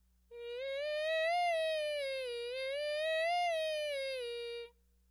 7) Simulating bone-transmission sound
I think it places somewhere between air and bone-conducted sounds.